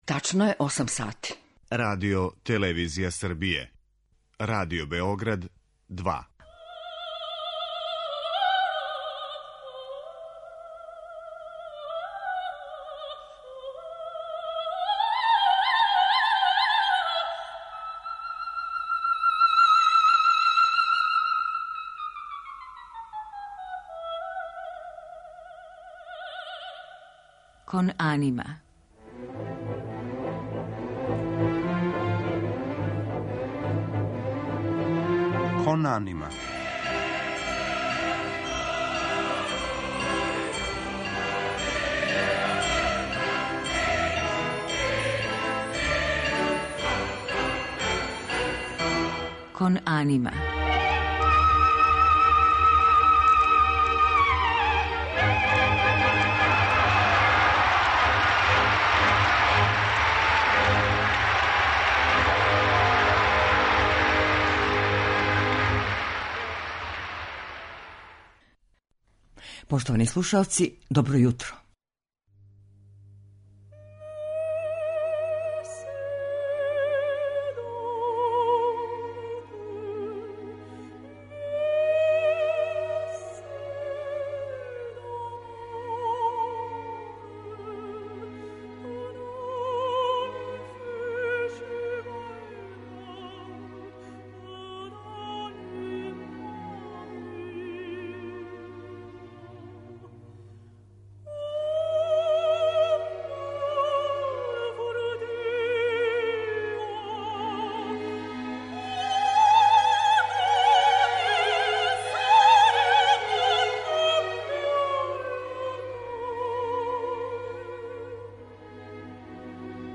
Радмила Бакочевић ће у данашњој емисији говорити о својим улогама, а у музичком делу емитоваћемо арије у њеном извођењу, као и у извођењу њених ученика.